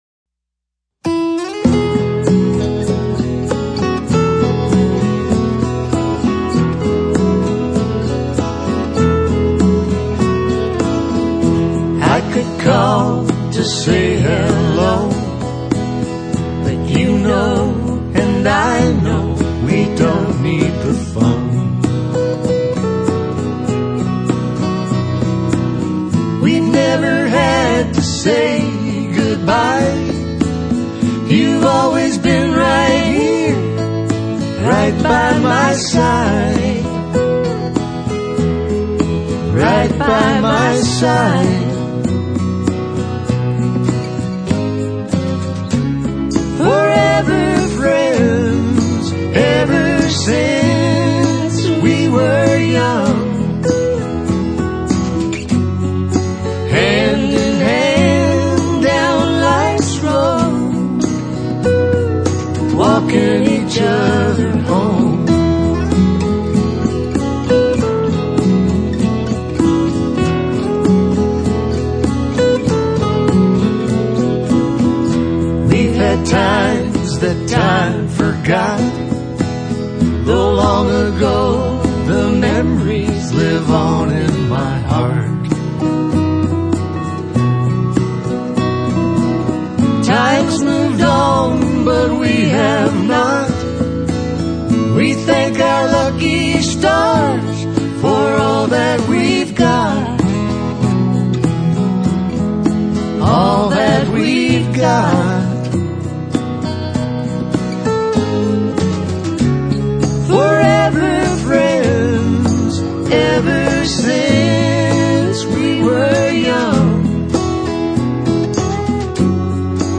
a beautiful duet
guitar